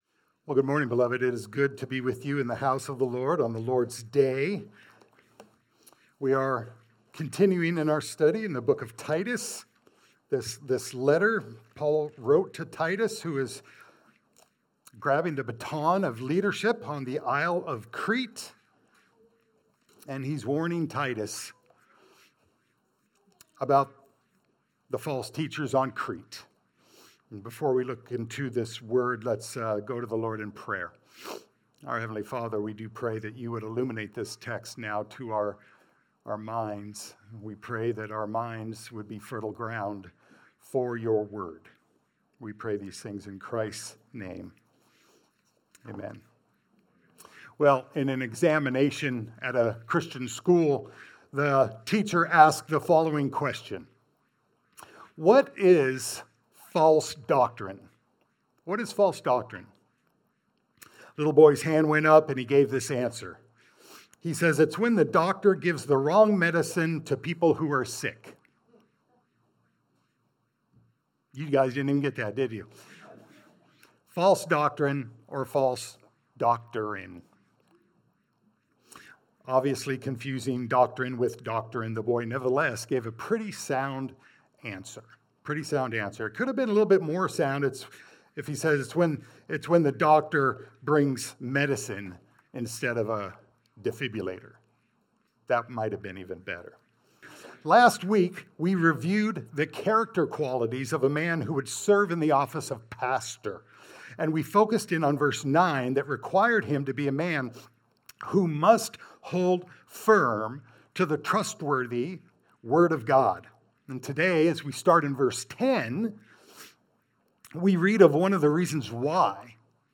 Titus 1:10-11 Service Type: Sunday Service « “Pastoral Qualifications